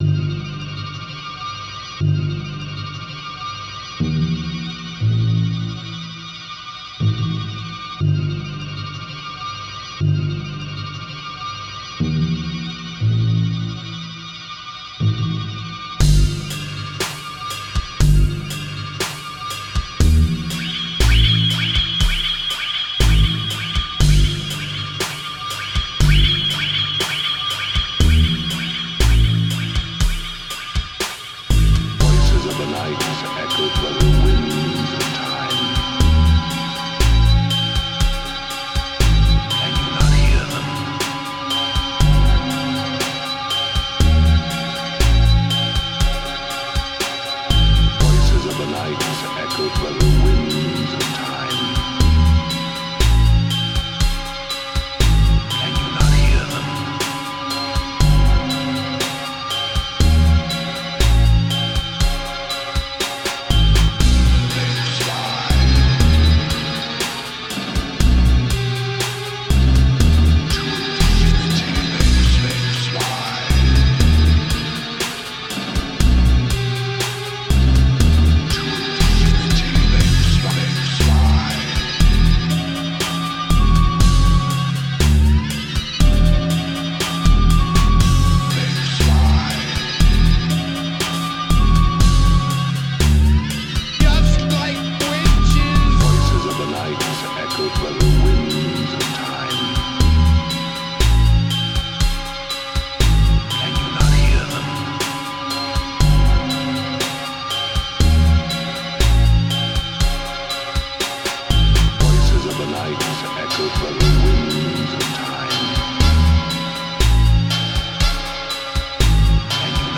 sample-based